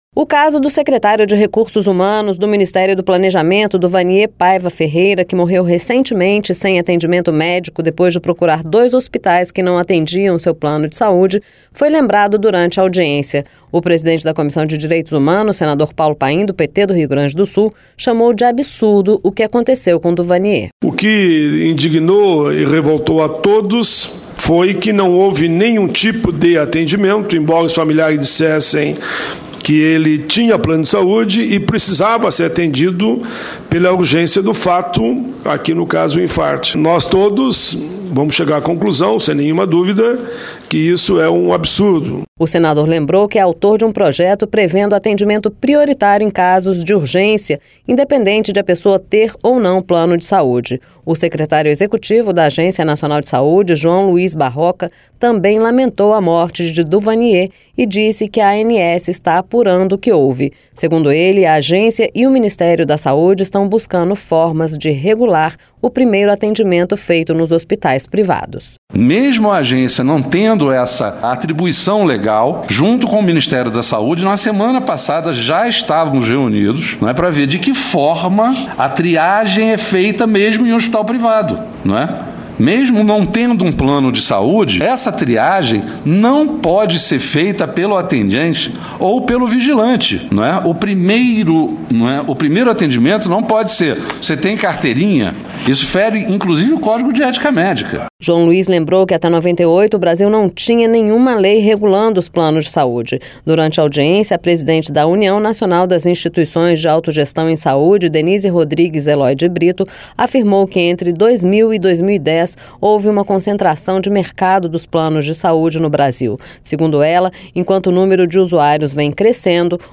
LOC: O MINISTÉRIO DA SAÚDE E A AGÊNCIA NACIONAL DE SAÚDE ESTÃO DISCUTINDO FORMAS DE REGULAR O PRIMEIRO ATENDIMENTO FEITO PELOS HOSPITAIS PRIVADOS NO BRASIL. LOC: A AFIRMAÇÃO FOI FEITA PELO SECRETÁRIO JOÃO LUÍS BARROCA, DA ANS, DURANTE AUDIÊNCIA NA COMISSÃO DE DIREITOS HUMANOS SOBRE A SITUAÇÃO DOS PLANOS DE SAÚDE NO BRASIL.